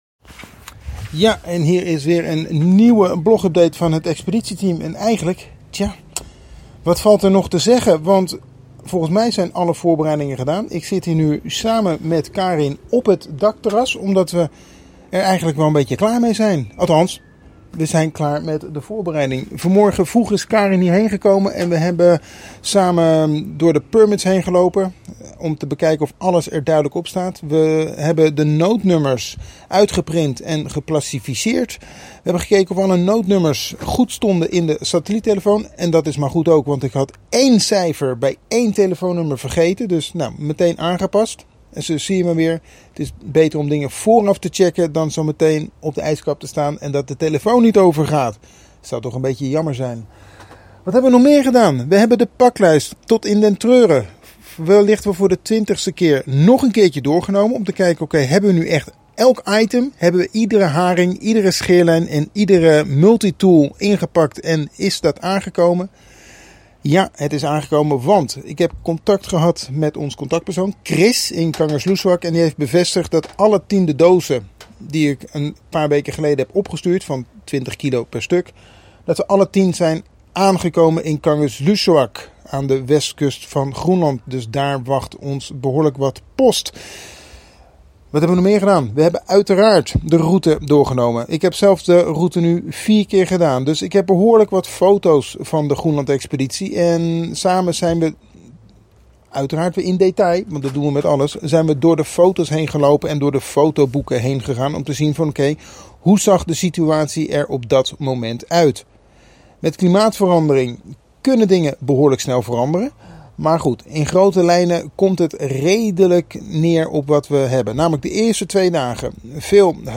Luister naar de Expeditie Groenland Update